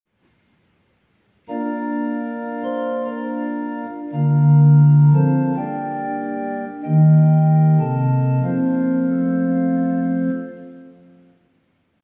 modulo alternativo per cantico Ap